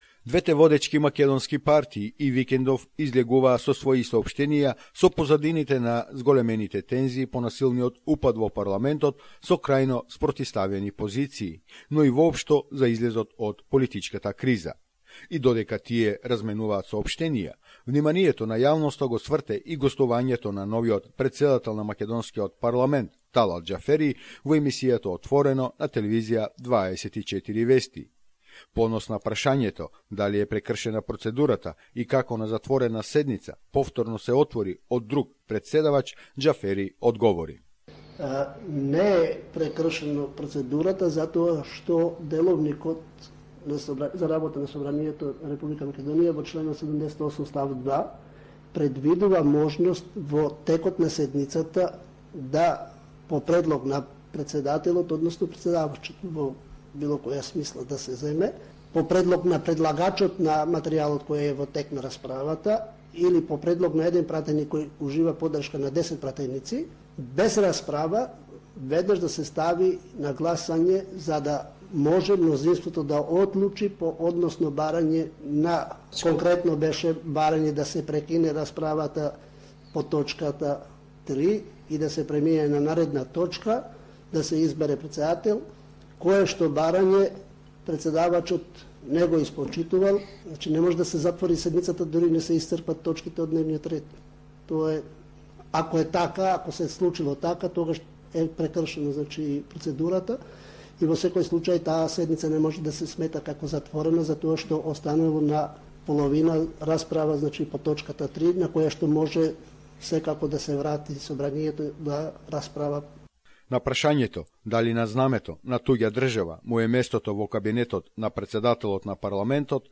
reports on the current political developments in the Republic of Macedonia.